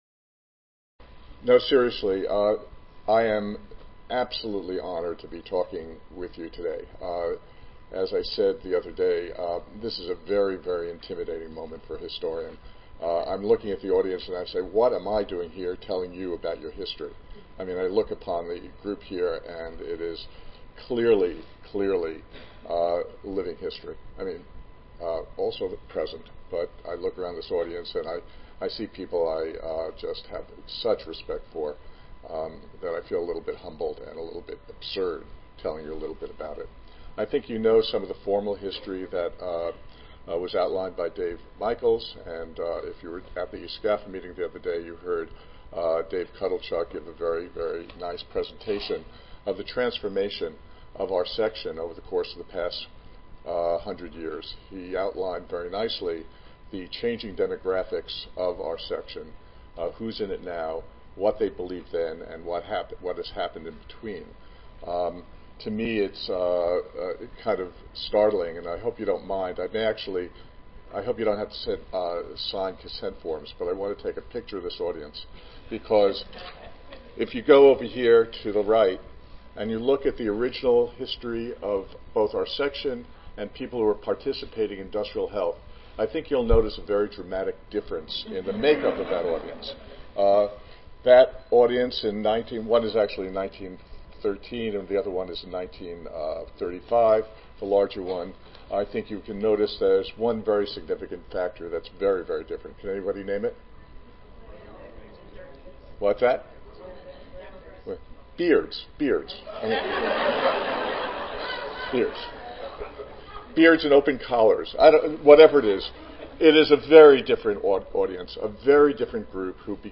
The keynote speaker and panel reflect on the occupational health and safety agenda: past, present, and the implications for the future on the threshold of the Section�s second century.